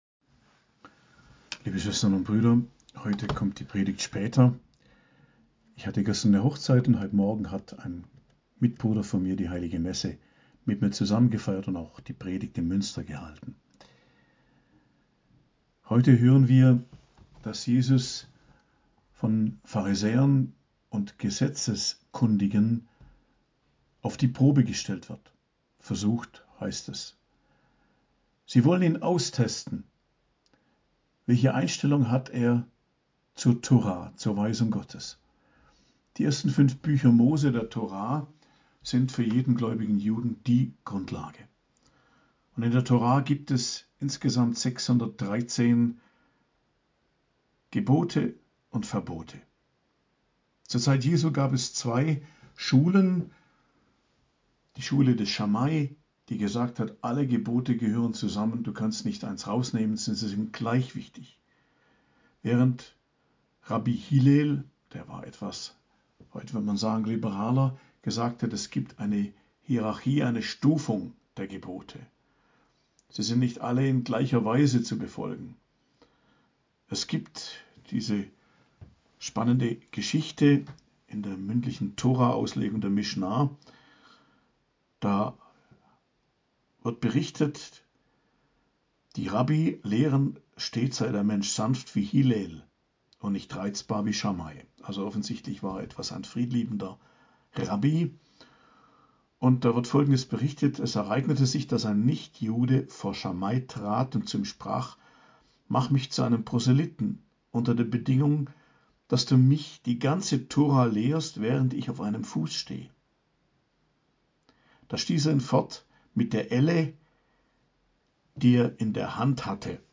Predigt zum 30. Sonntag i.J., 29.10.2023